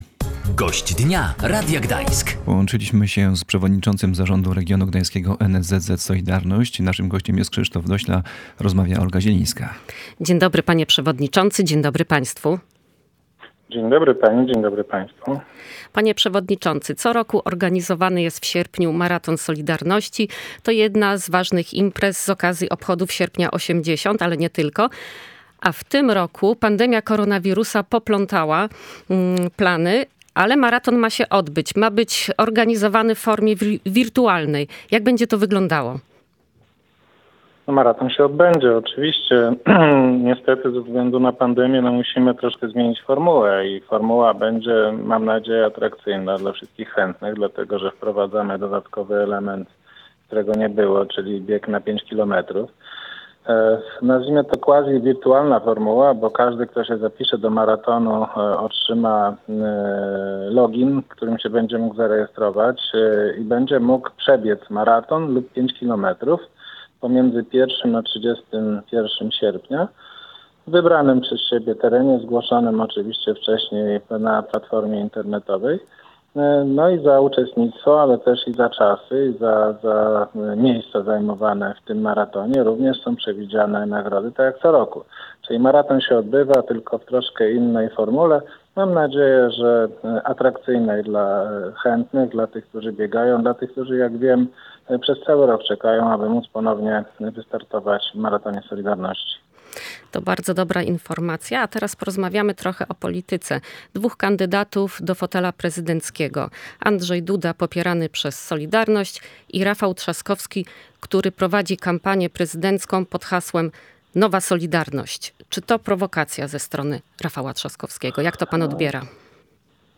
- Rafał Trzaskowski nigdy nie był blisko z "Solidarnością" i źle o związku się wypowiadał - mówił w Radiu Gdańsk